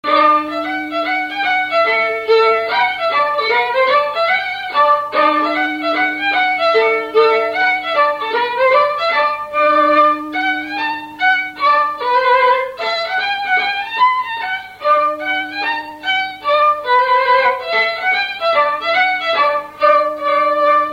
Mazurka
Saint-Hilaire-de-Chaléons
Résumé instrumental
Pièce musicale inédite